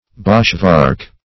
Search Result for " boshvark" : The Collaborative International Dictionary of English v.0.48: Boshvark \Bosh"vark\, n. [D. bosch wood + varken pig.]